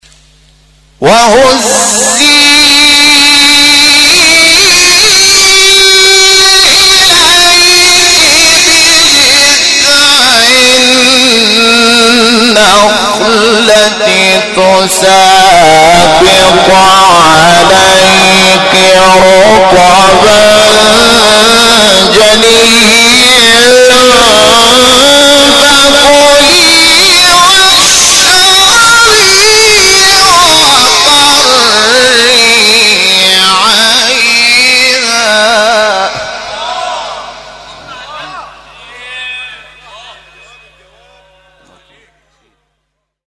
سوره: مریم آیه: 25-26 استاد: محمود شحات مقام: رست قبلی بعدی